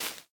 Minecraft Version Minecraft Version 25w18a Latest Release | Latest Snapshot 25w18a / assets / minecraft / sounds / block / big_dripleaf / step4.ogg Compare With Compare With Latest Release | Latest Snapshot
step4.ogg